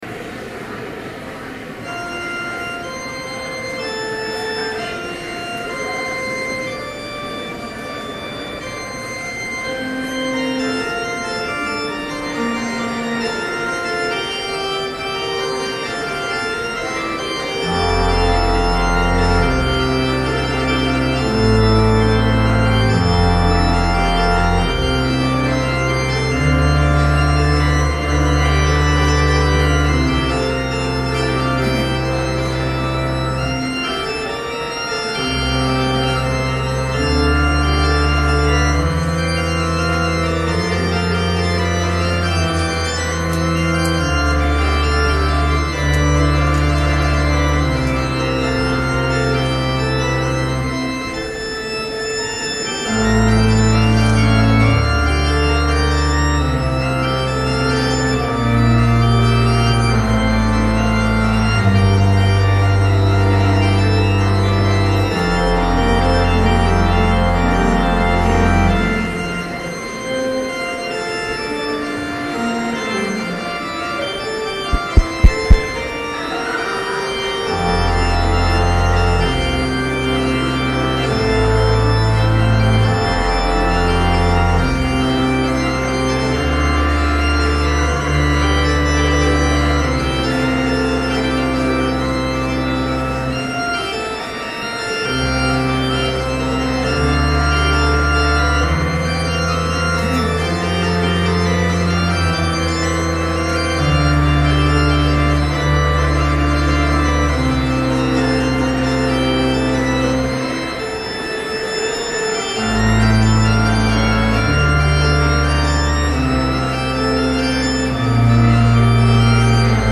Complete service audio for Chapel - January 9, 2012